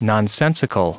Prononciation du mot nonsensical en anglais (fichier audio)
Prononciation du mot : nonsensical